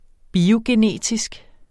Udtale [ biogeˈneˀtisg ]